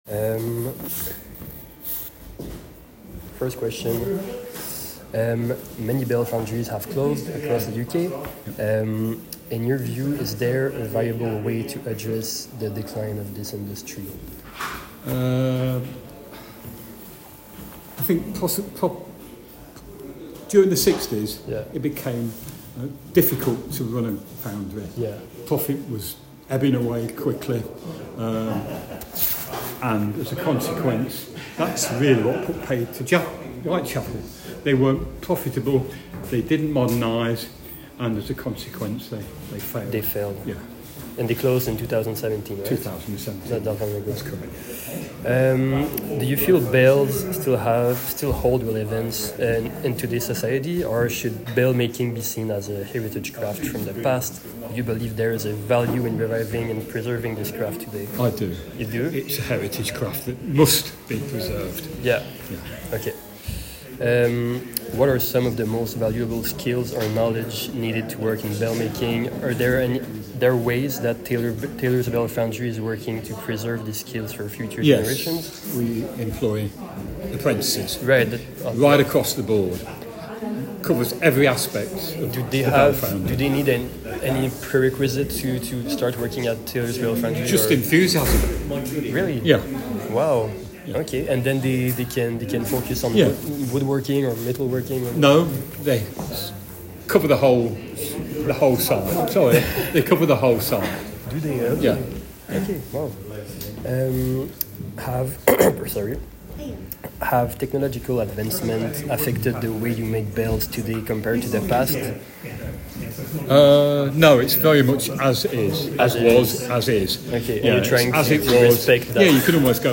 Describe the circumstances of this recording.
As part of my exploration, I visited Taylor’s Bellfoundry in Loughborough, the last operational bell foundry in the United Kingdom.